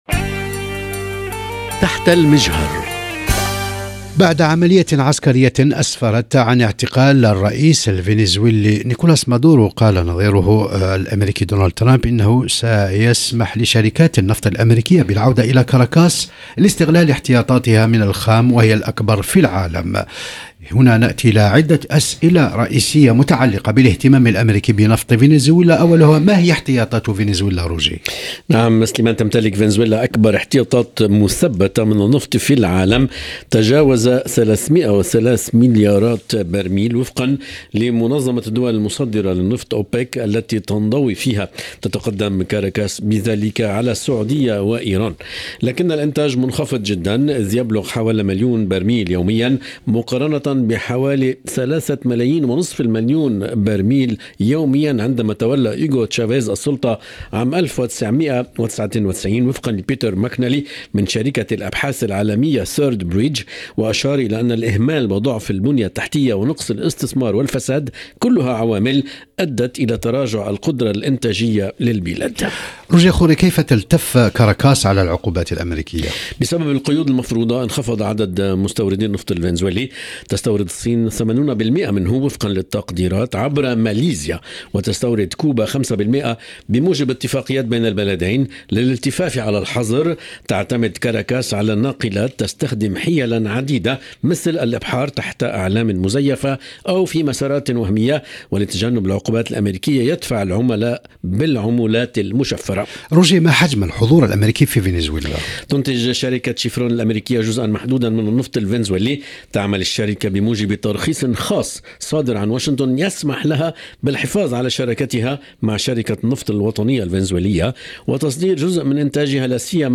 حوار إذاعي